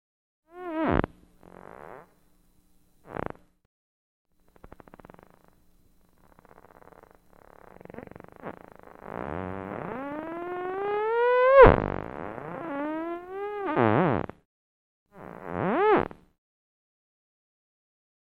Звуки дельфинов
Слушайте онлайн или скачивайте треки в высоком качестве: игривые щелчки, мелодичные свисты и ультразвуковые волны, создающие атмосферу океанского спокойствия.
А еще такой звук издает дельфин в воде